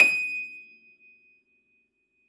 53f-pno23-D5.aif